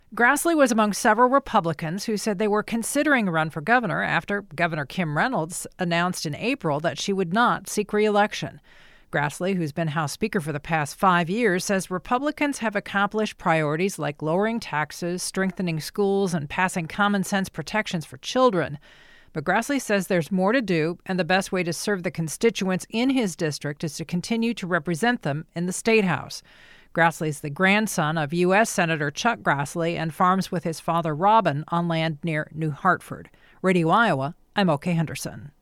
reports.